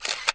camera_shutter_1.wav